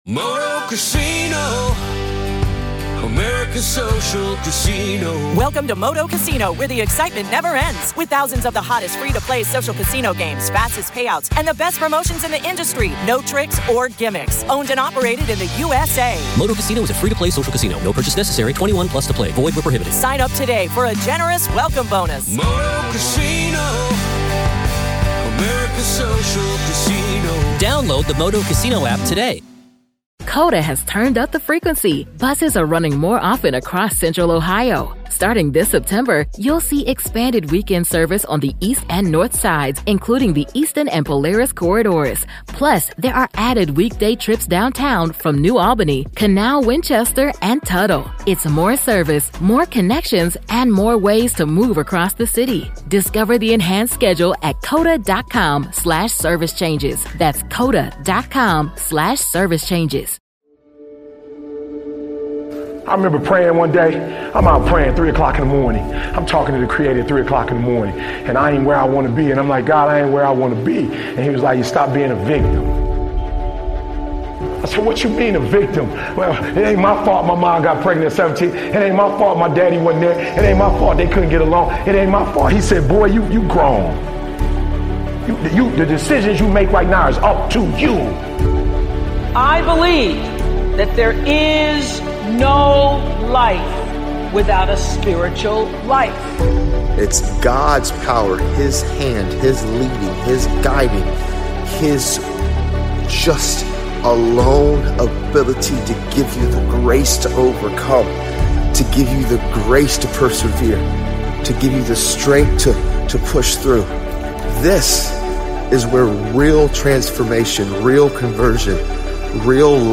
Speakers: Eric Thomas